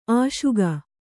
♪ āśuga